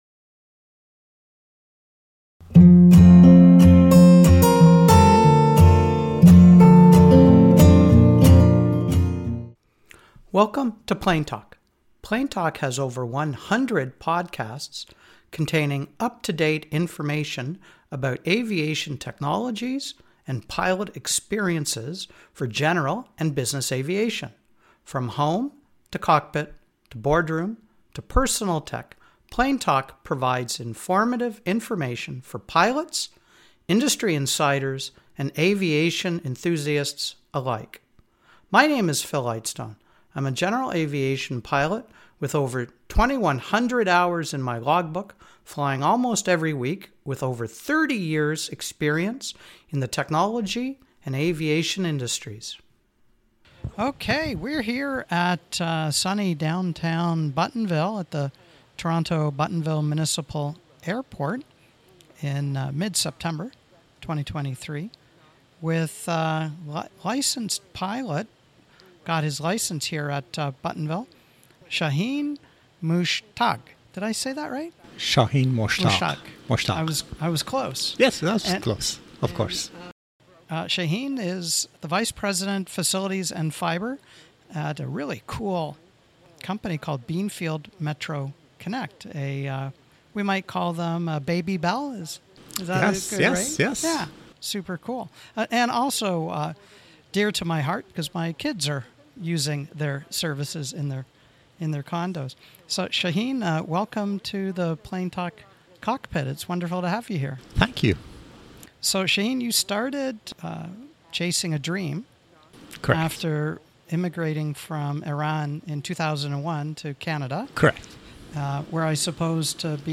Recorded on location at the Toronto Buttonville Municipal Airport (CYKZ)